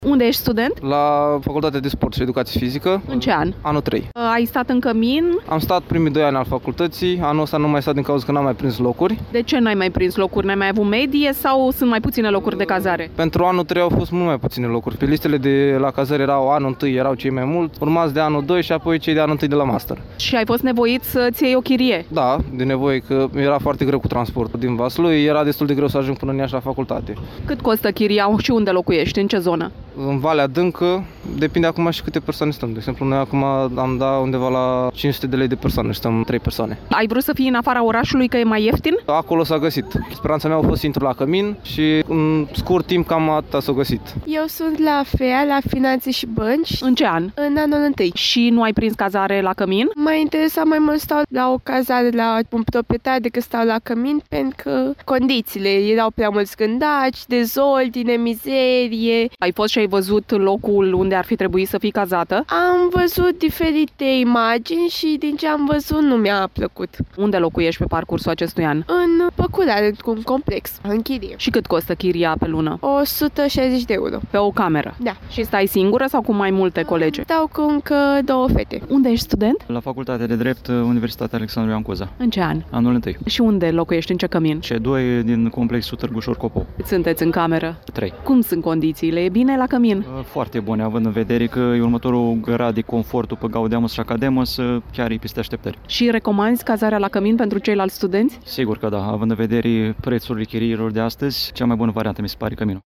2-oct-vox-pop.mp3